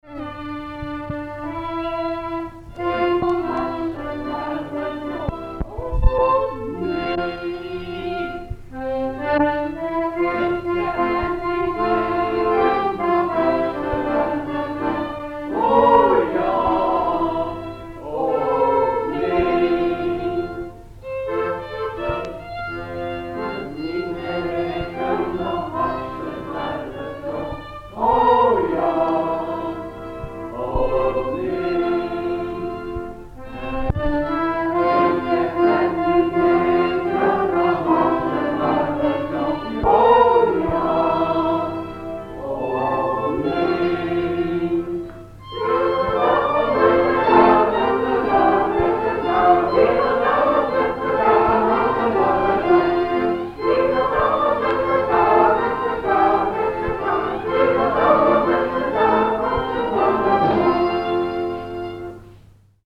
Twentse muziek in het dialect.
Lied De Hoksebarger